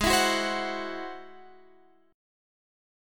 Ab+7 chord